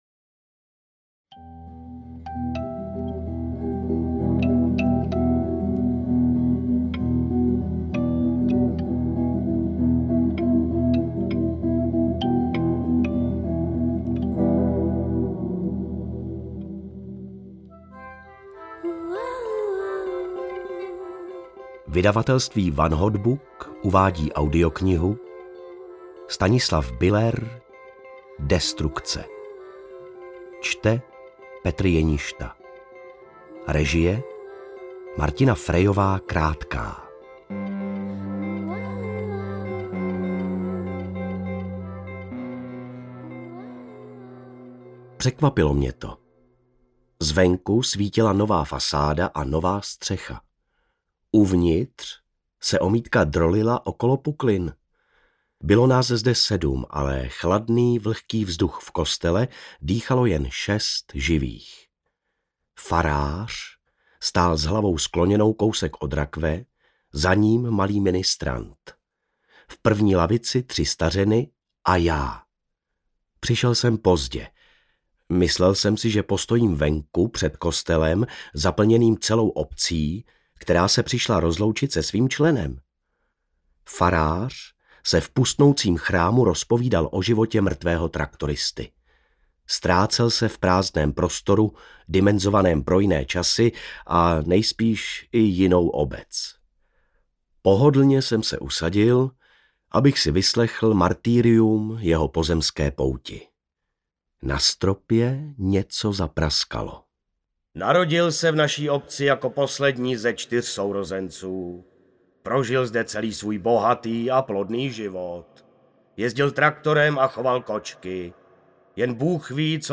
Destrukce audiokniha
Ukázka z knihy